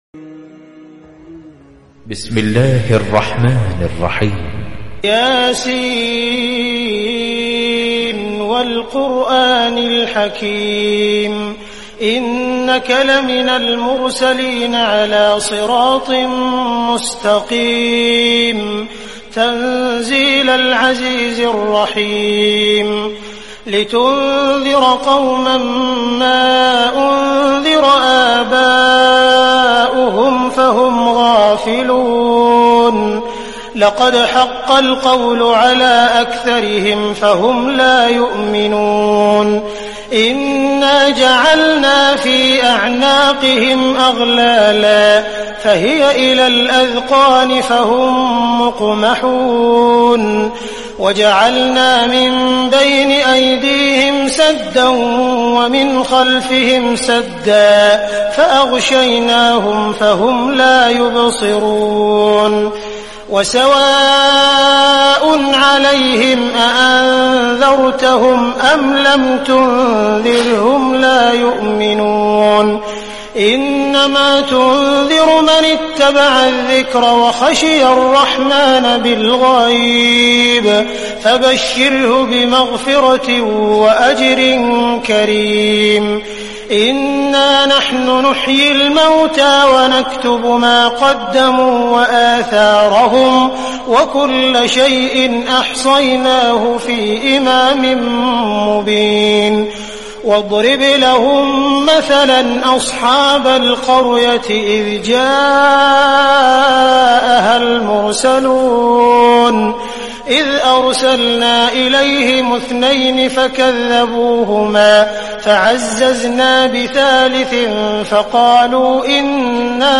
Listen to Surah Yaseen in the melodious voice of Qari Sudais, Imam-e-Kaaba.
Surah Yaseen Tilawat by Qari Sudais | Audio (MP3) Recitation
Surah-Yaseen-Complete-Beautiful-Recitation-Qari-Sudais-Quran-36-64.mp3